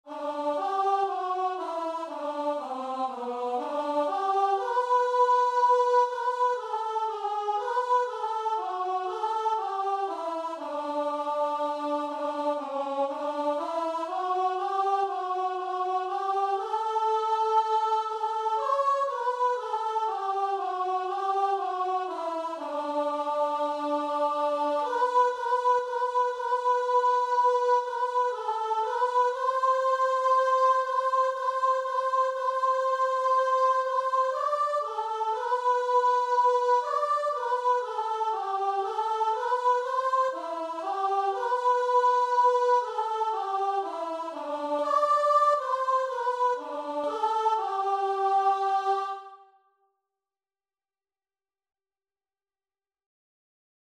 Christian
6/4 (View more 6/4 Music)
Guitar and Vocal  (View more Easy Guitar and Vocal Music)
Classical (View more Classical Guitar and Vocal Music)